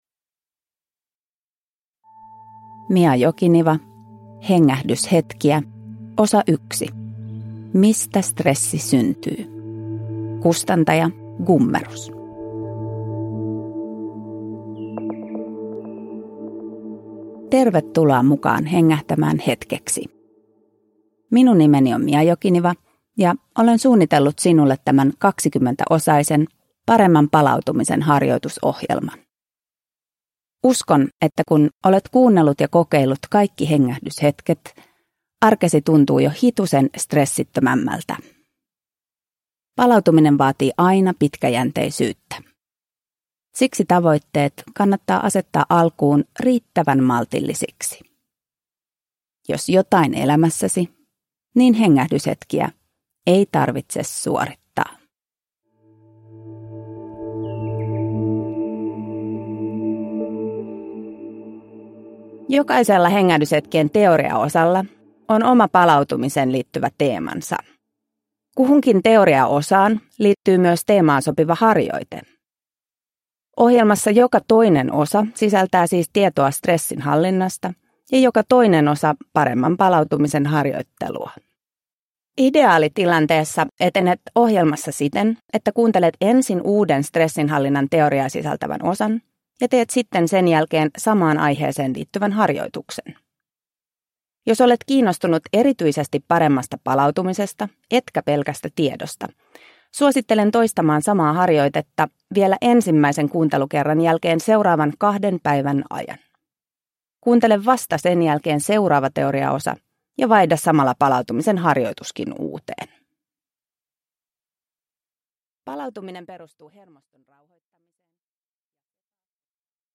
Hengähdyshetkiä (ljudbok